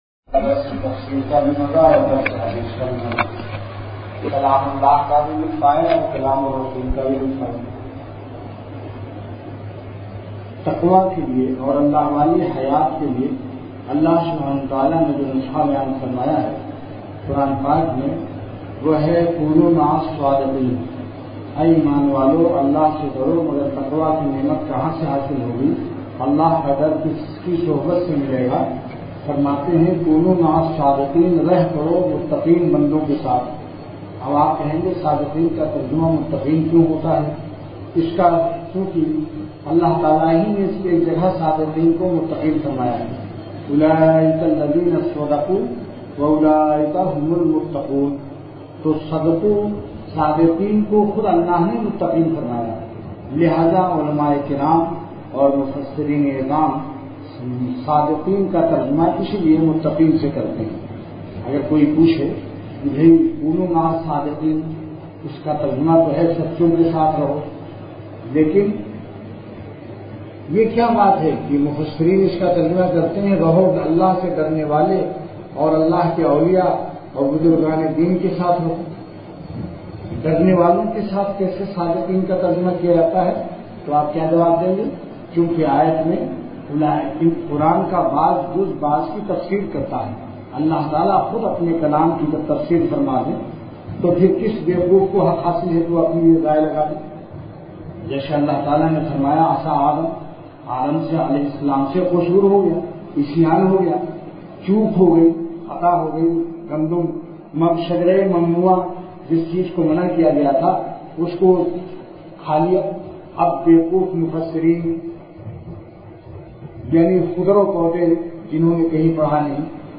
Delivered at Khanqah Imdadia Ashrafia.
Event / Time After Isha Prayer